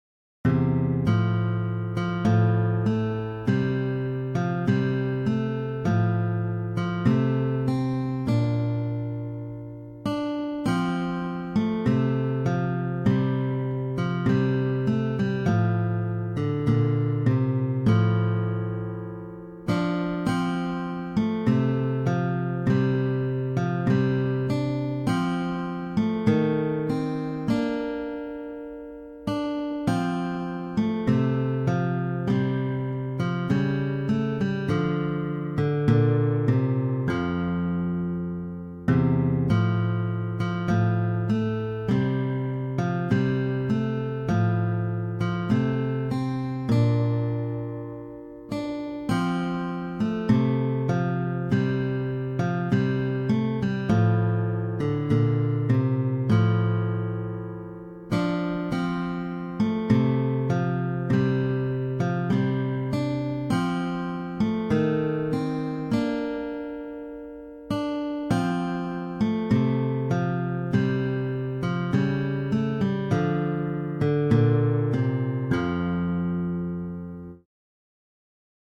traditional Scottish melody
for guitar